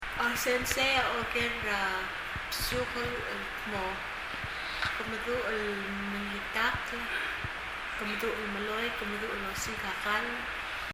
発音　　　英訳：